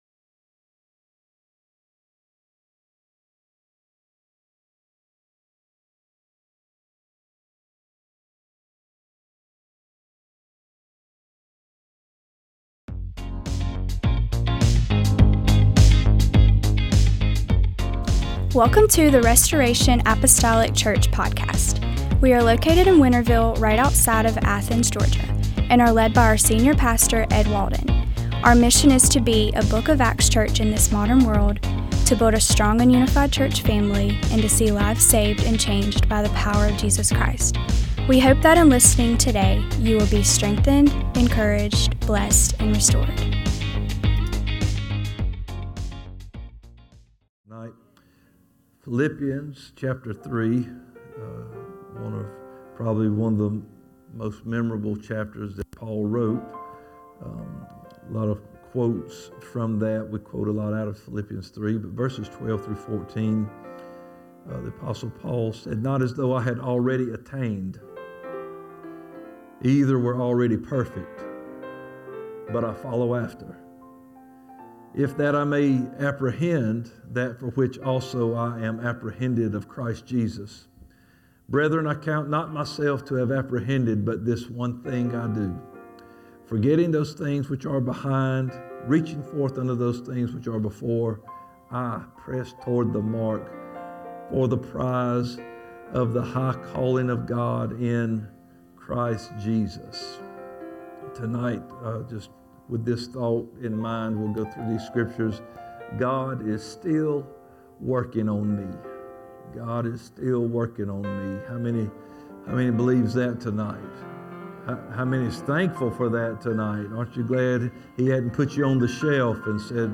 MDWK Service